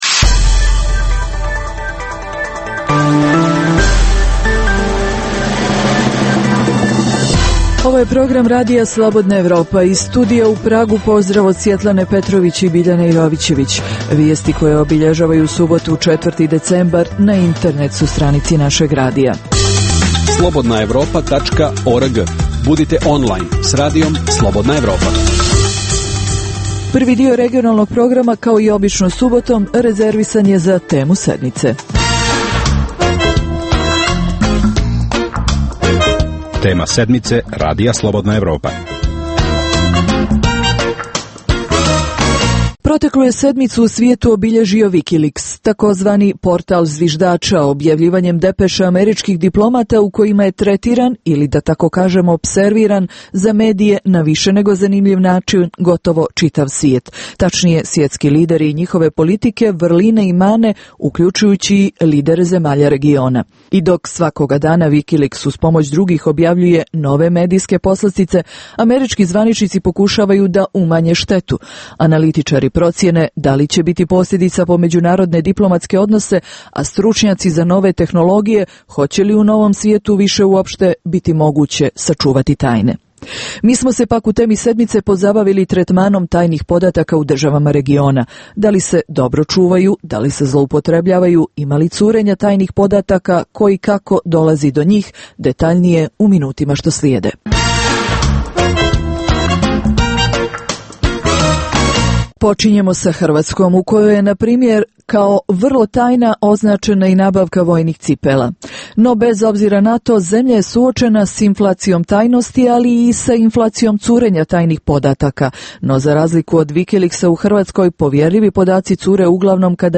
Preostalih pola sata emisije, nazvanih "Tema sedmice" sadrži analitičke teme, intervjue i priče iz života, te rubriku "Dnevnik", koji za Radio Slobodna Evropa vode poznate osobe iz regiona.